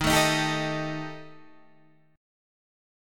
D# Major Flat 5th